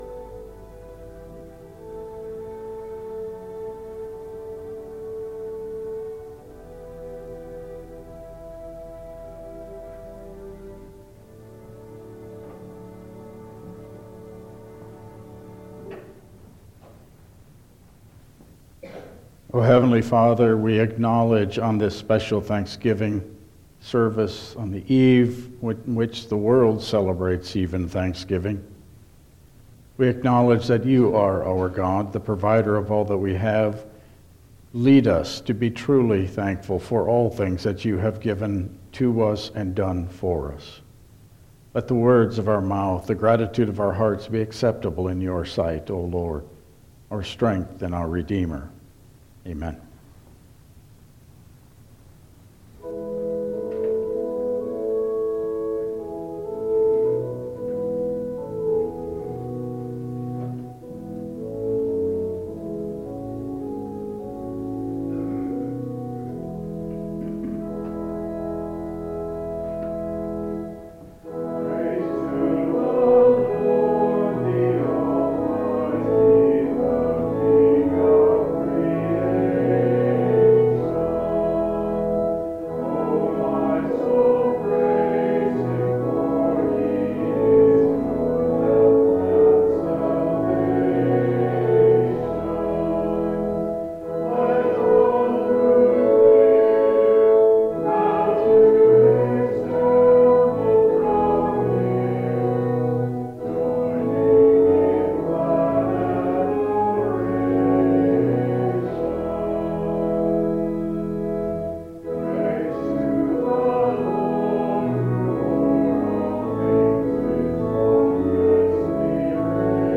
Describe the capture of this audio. Service Type: Thanksgiving Service